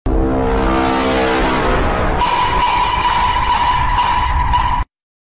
bass boosted imposter sound sound effects free download